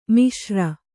♪ miśra